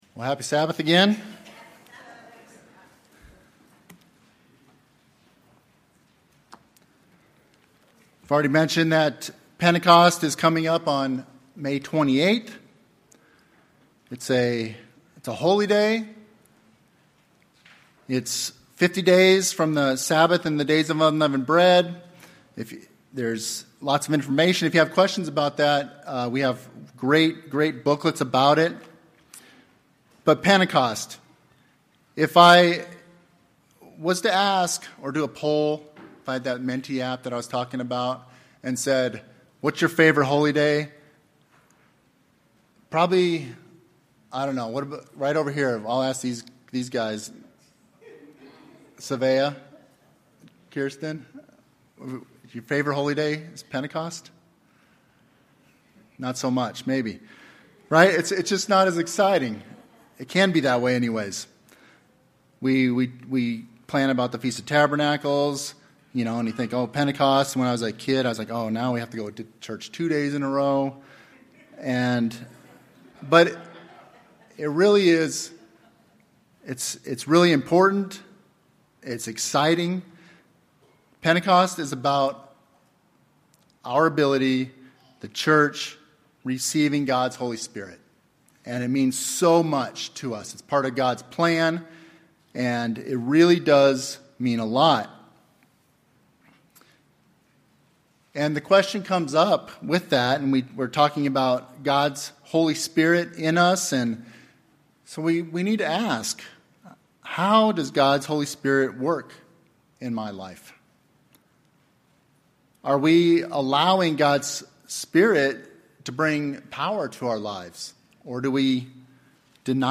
Sermon About a Sermon
Given in Phoenix Northwest, AZ